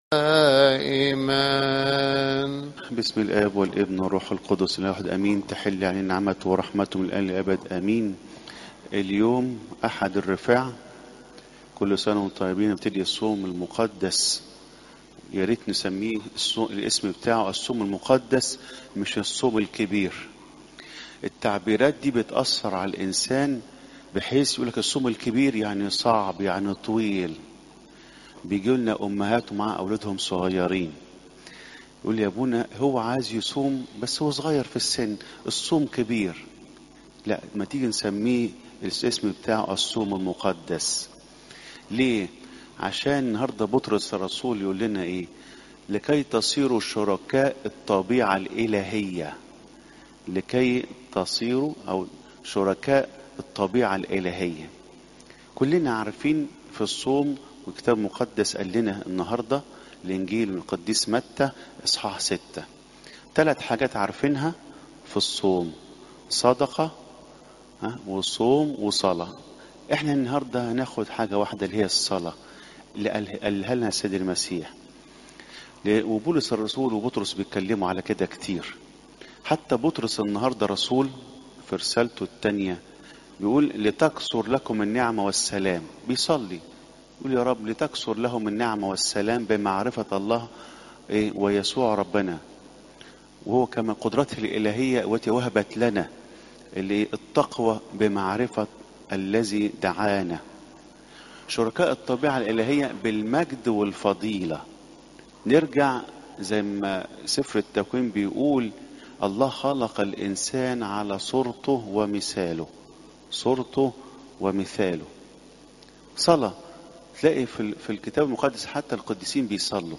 Sermons
23 February 2025 10:04 Church's Holly Masses Hits